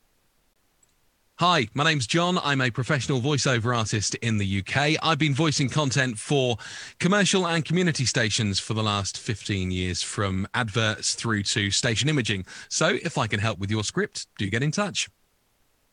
外籍英式英语